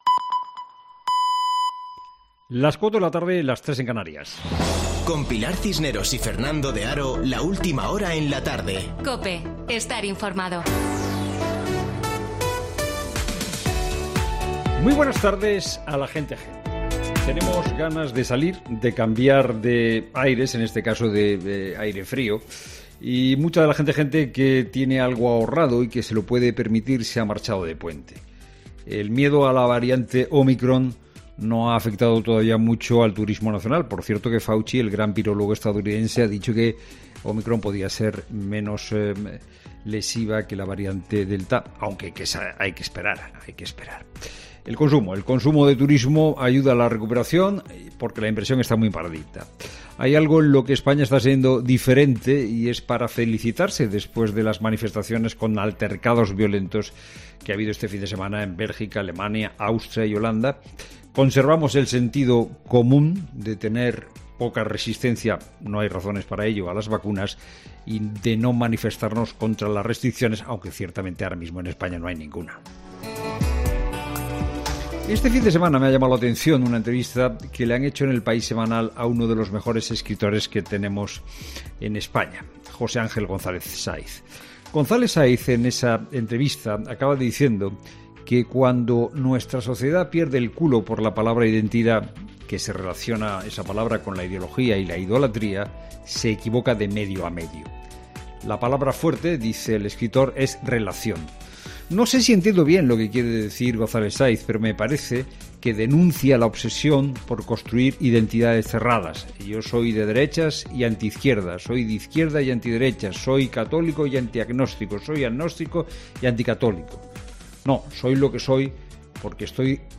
Boletín de noticias COPE del 6 de diciembre de 2021 a las 16.00 horas